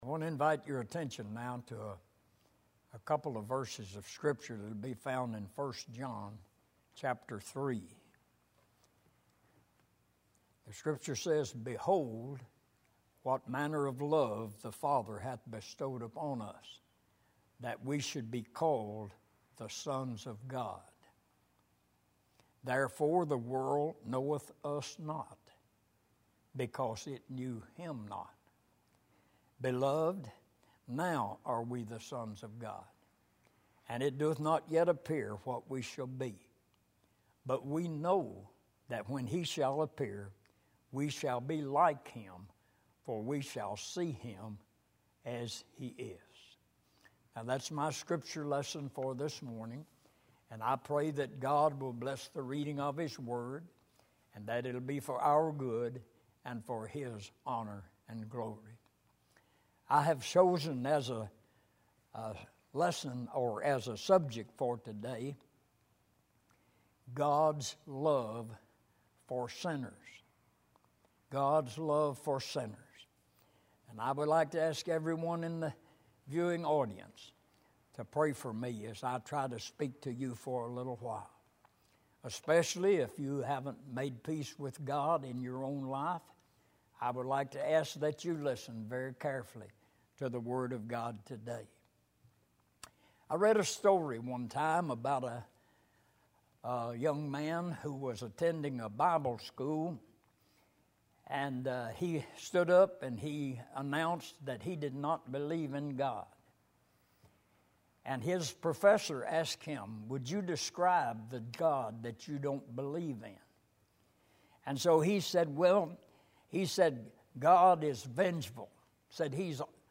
Passage: 1 John 3:1,2 Service Type: Sunday Evening